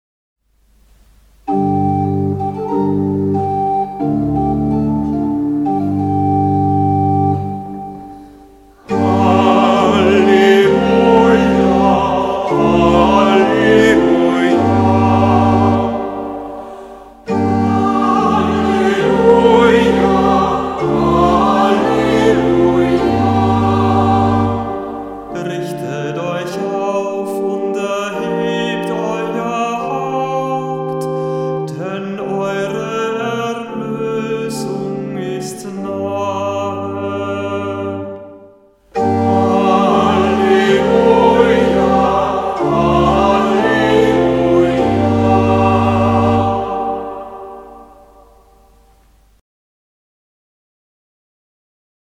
Ruf vor dem Evangelium - November 2025
Hörbeispiele aus dem Halleluja-Büchlein
Kantor wenn nicht anders angegeben